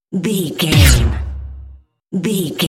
Dramatic hit deep electronic
Sound Effects
Atonal
heavy
intense
dark
aggressive